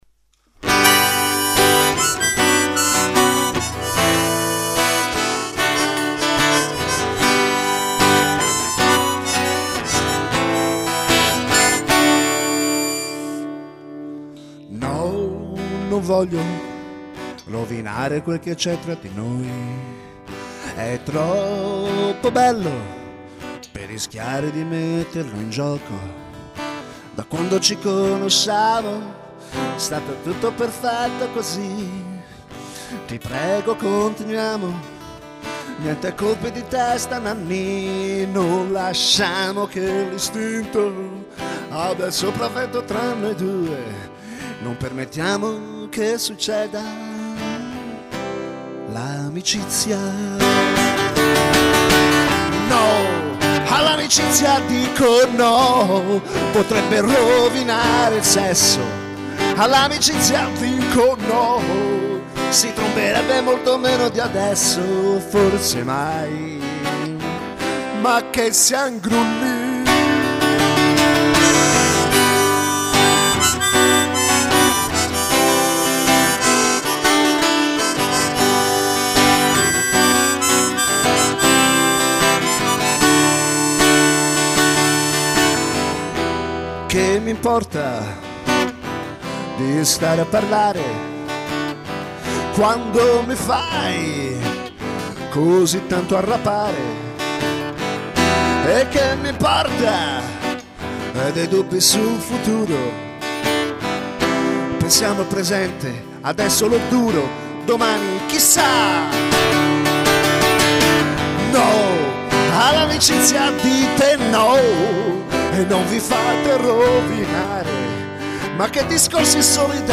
Una canzone introspettiva, sul delicato rapporto tra sesso e amicizia e sui complicati risvolti che ne conseguono. Un tema sicuramente già ampiamente affrontato da molte altre canzoni, a cui aggiungo il mio delicato punto di vista in una registrazione intimista a chitarra e voce, come ai vecchi tempi